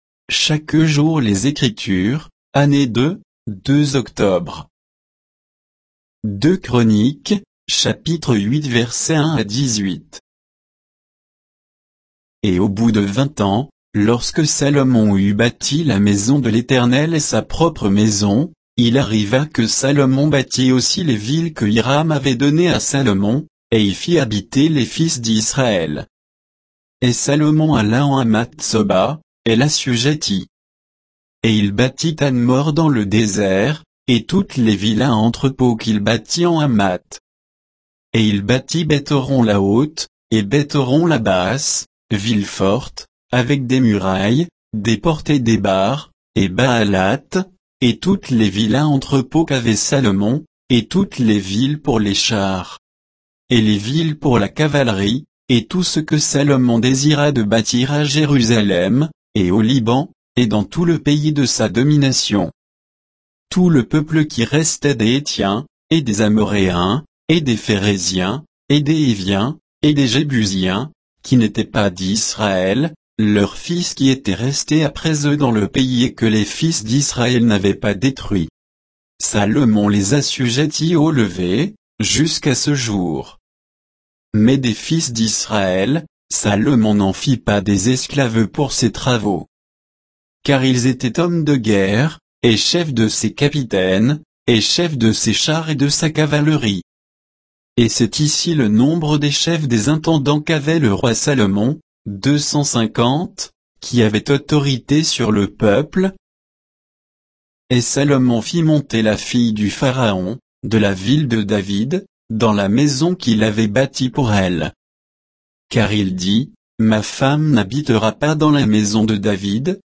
Méditation quoditienne de Chaque jour les Écritures sur 2 Chroniques 8, 1 à 18